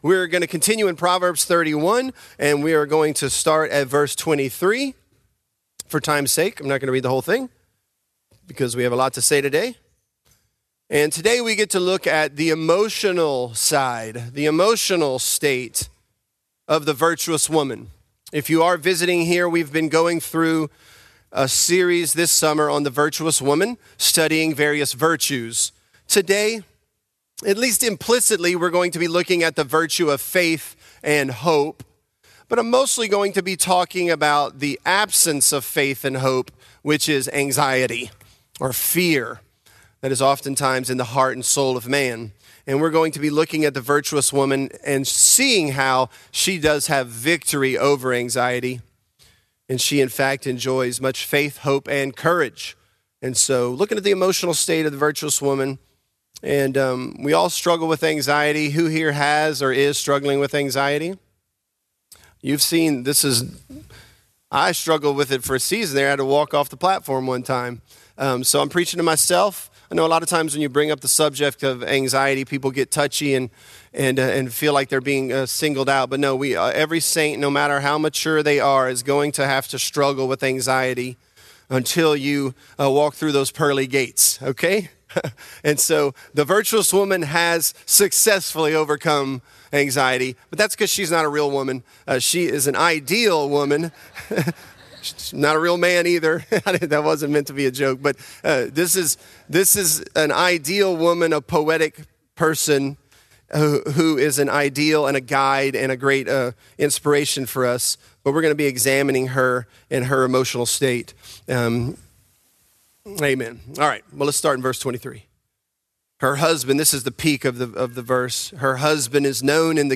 Virtuous: She Laughs at The Time to Come | Lafayette - Sermon (Proverbs 31)
This is a part of our sermon series, "Virtuous."